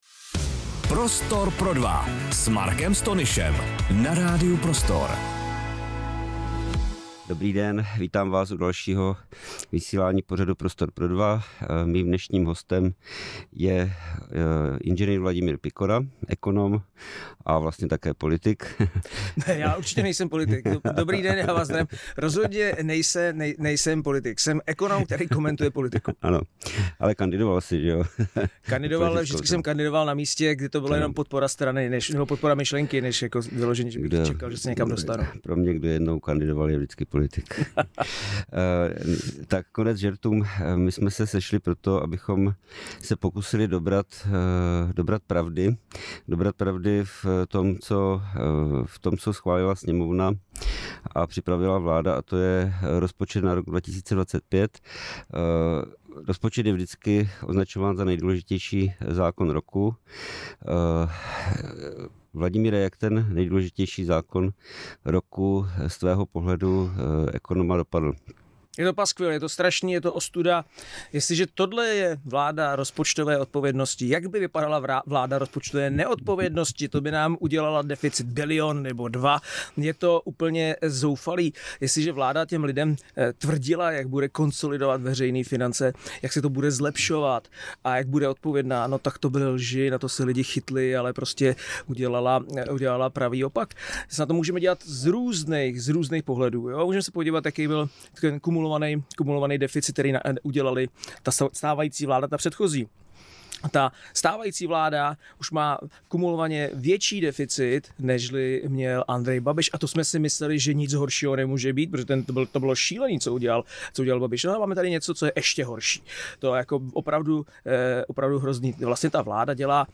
Podle něj vláda pracuje s nereálnými odhady ekonomického růstu a ignoruje rizika globální recese. Rozhovor se dotkl také situace v Evropě, role Donalda Trumpa a budoucnosti, kterou podle Pikory lépe symbolizuje Elon Musk.